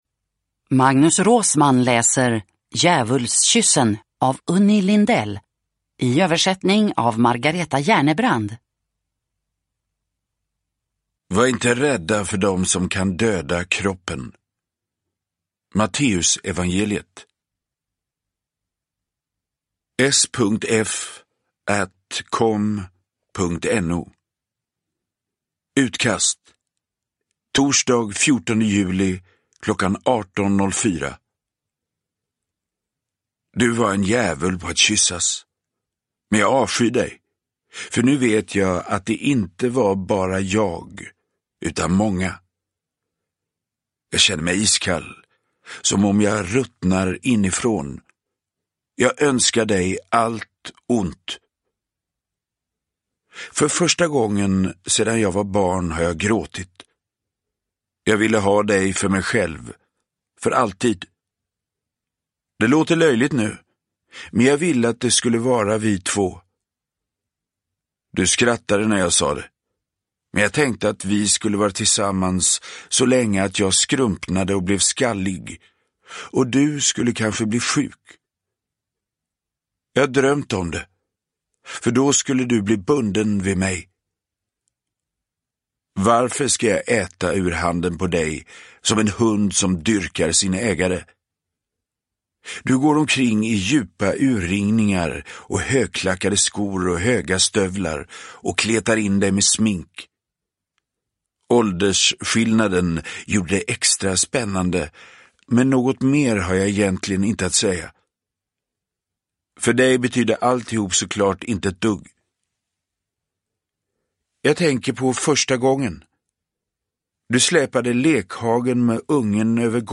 Djävulskyssen / Ljudbok